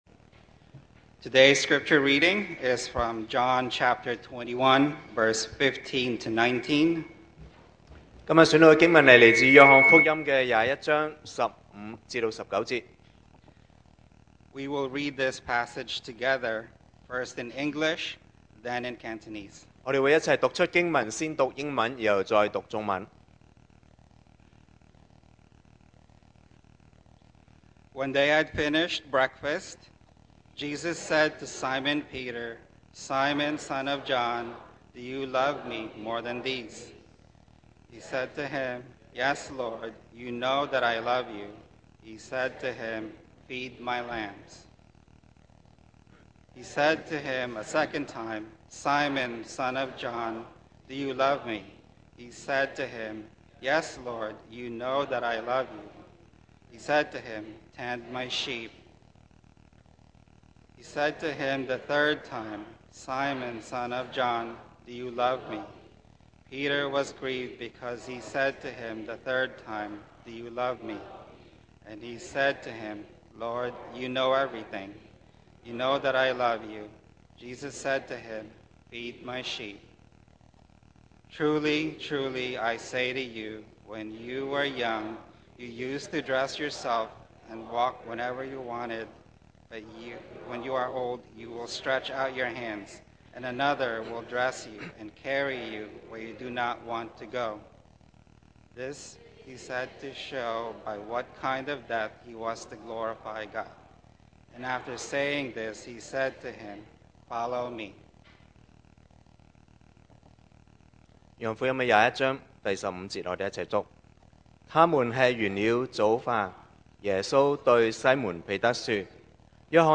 Series: 2025 sermon audios 2025年講道重溫 Passage: John 21:15-19 Service Type: Sunday Morning Yes